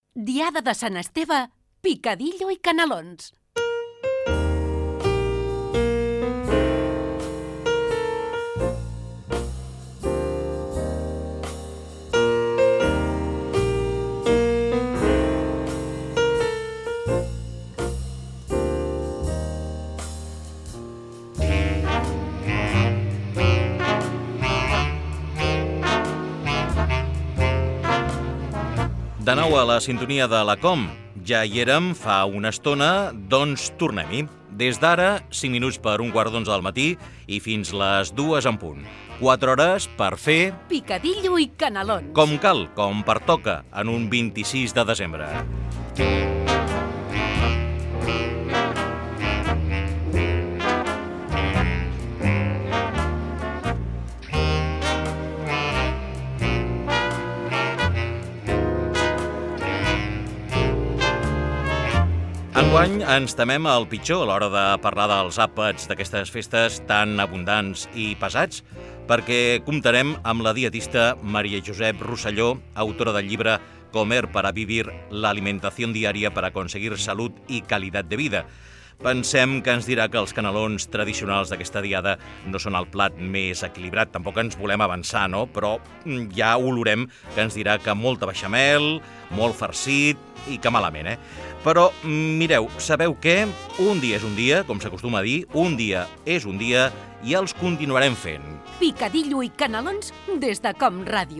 Inici de la segona hora del programa del dia de Sant Esteve
Entreteniment